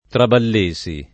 Traballesi [ traball %S i ] cogn.